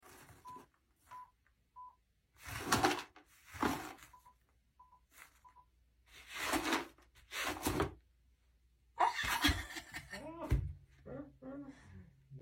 729 2 layers of sweeping sound effects free download